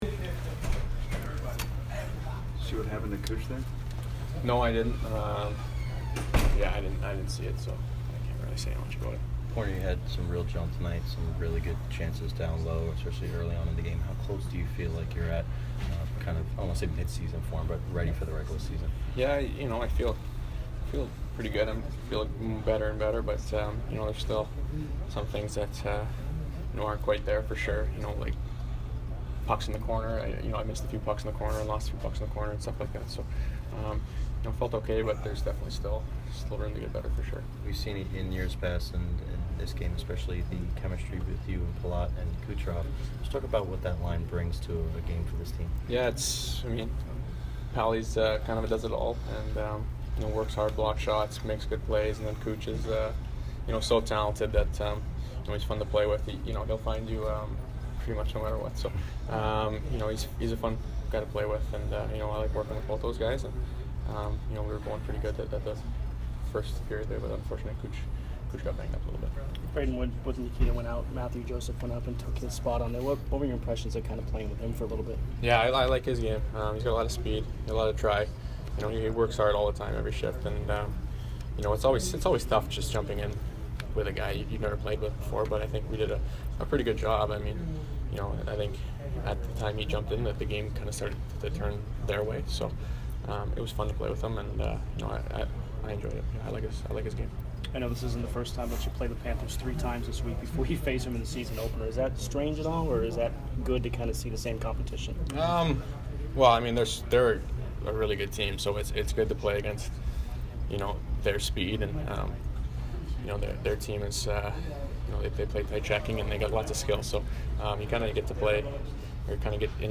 Brayden Point post-game 9/25